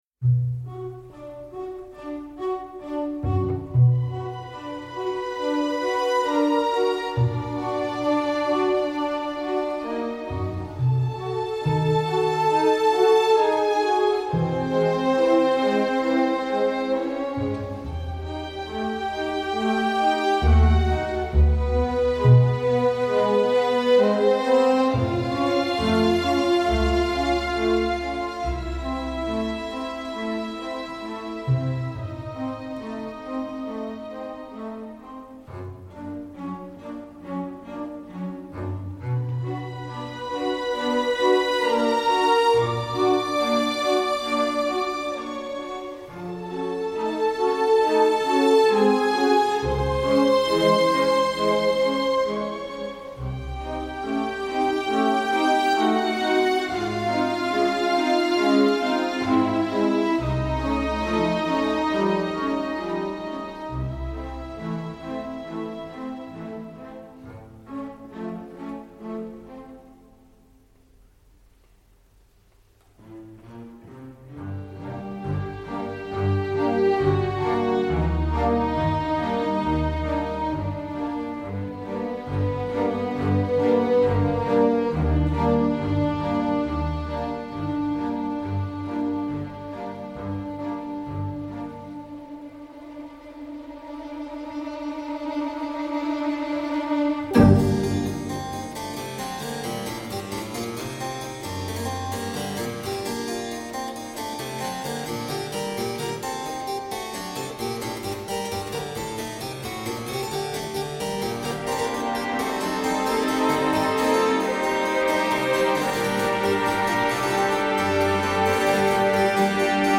Clavecin et cordes tendues